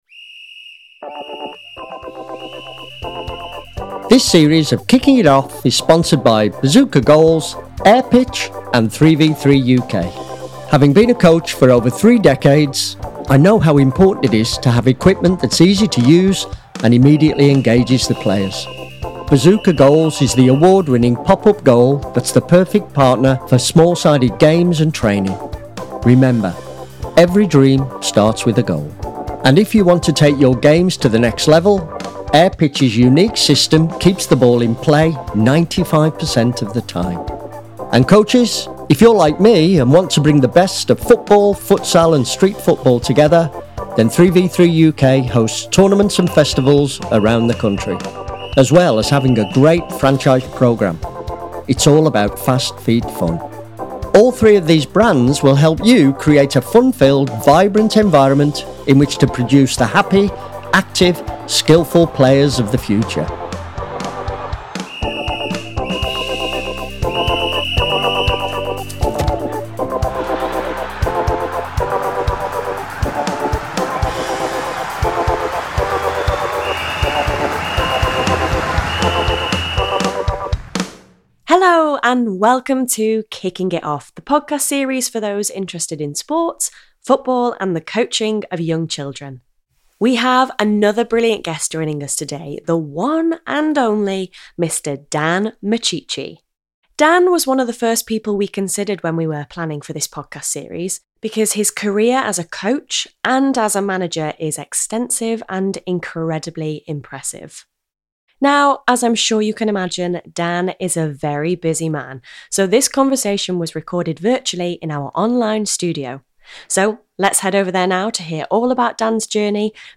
His wealth of managing and coaching experience makes for a conversation you don't want to miss.